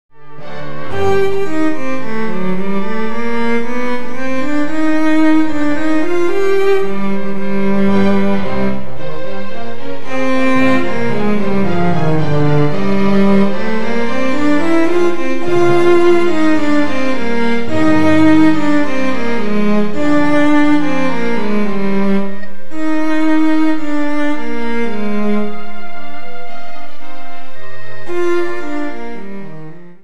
〔オーケストラ・パート〕 　オーケストラ音で収録